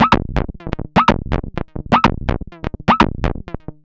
tx_perc_125_lostit.wav